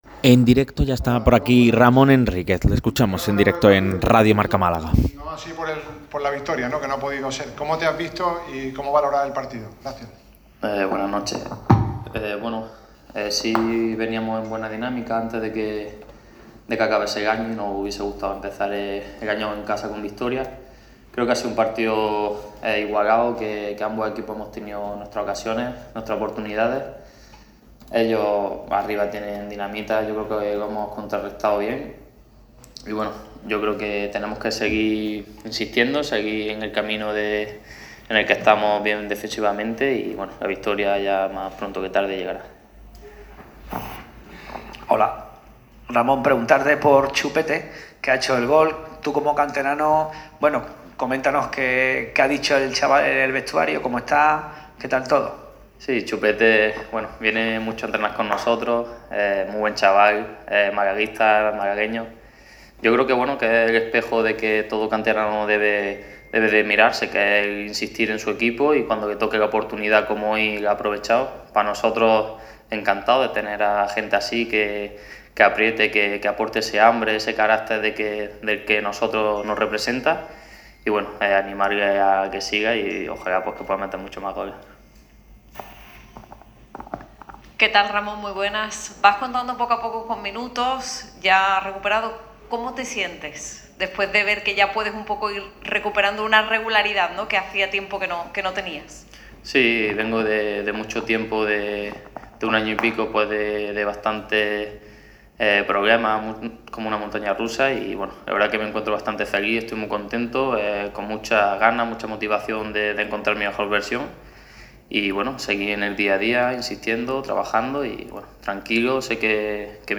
en la sala de prensa de La Rosaleda.